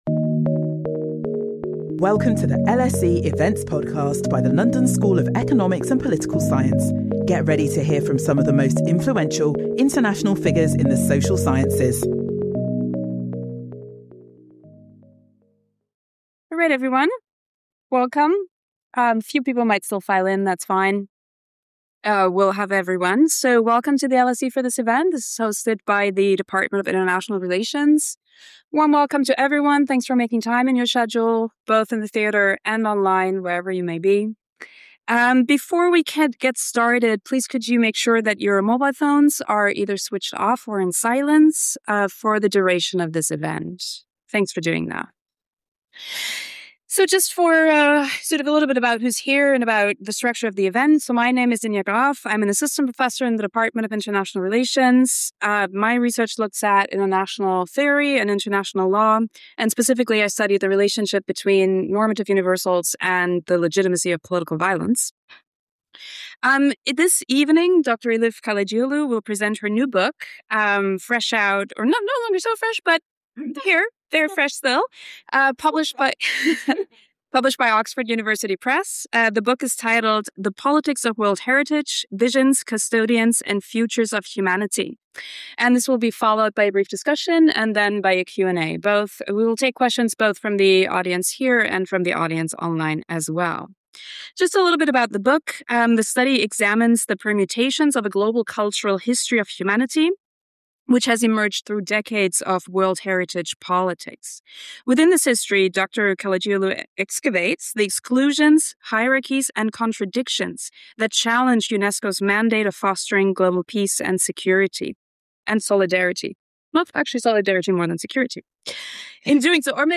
LSE: Public lectures and events